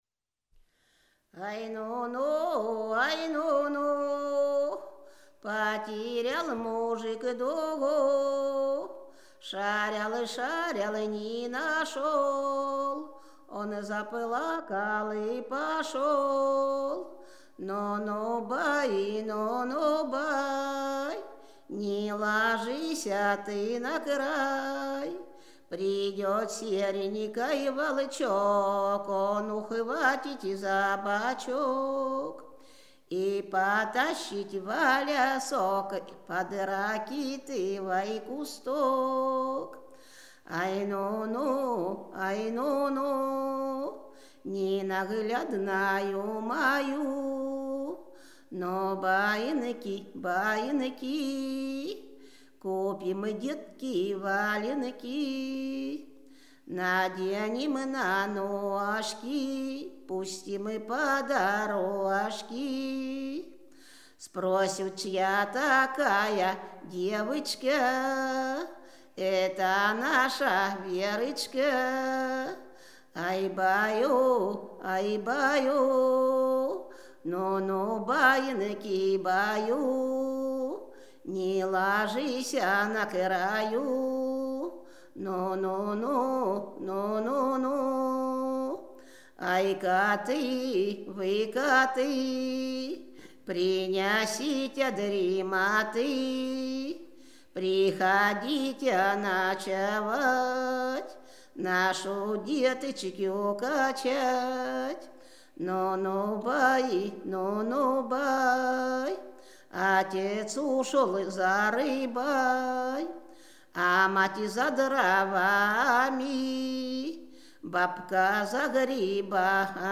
Рязань Секирино «Ай, нуну, ай, нуну», байканье.